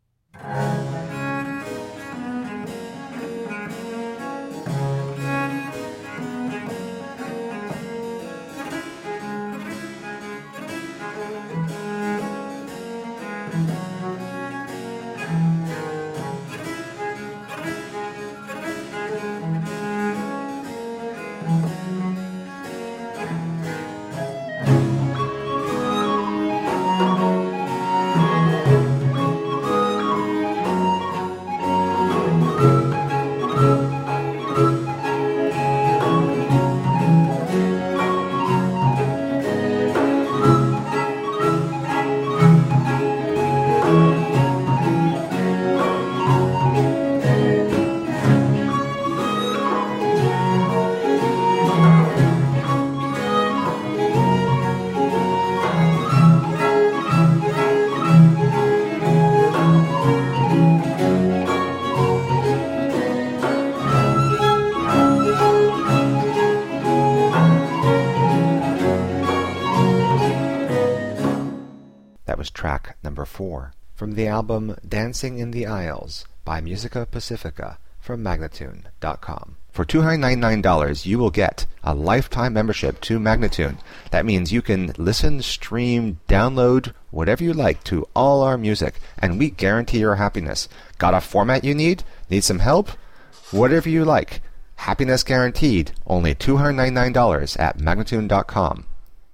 recorders and whistle
baroque violins